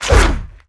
swish.wav